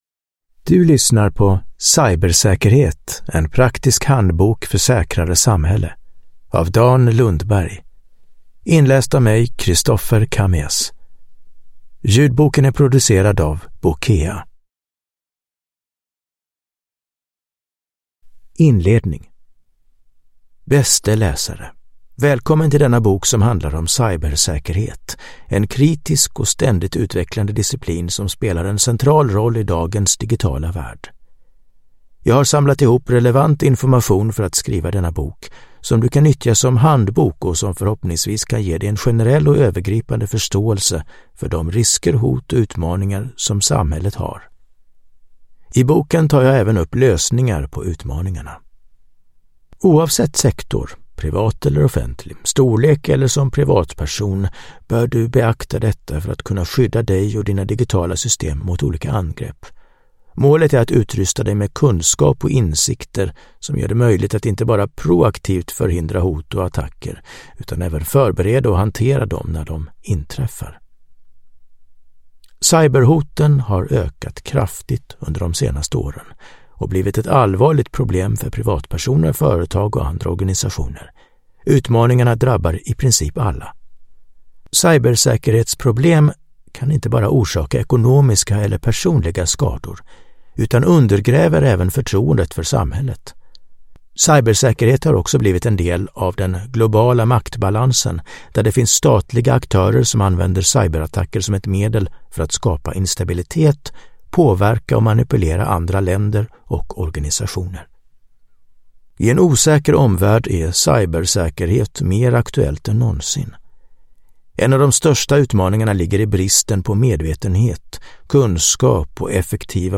Cybersäkerhet : en praktisk handbok för säkrare samhälle – Ljudbok